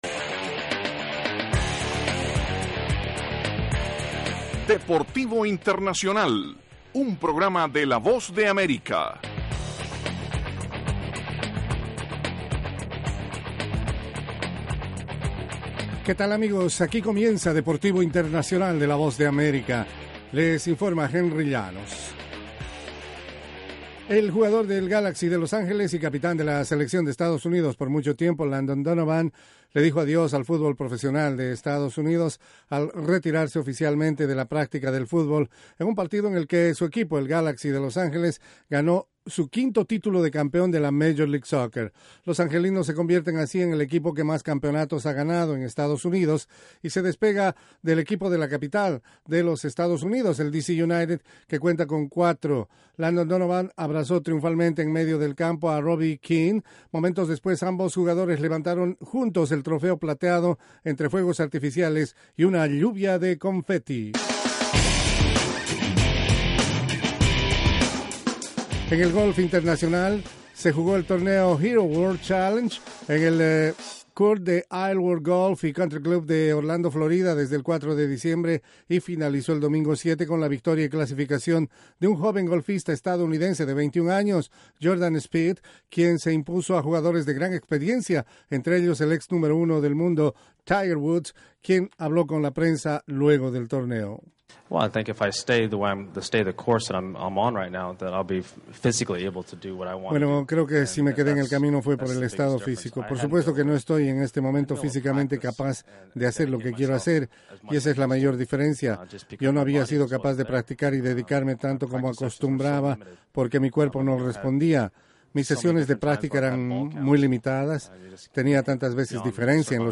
presenta las noticias más relevantes del mundo deportivo desde los estudios de la Voz de América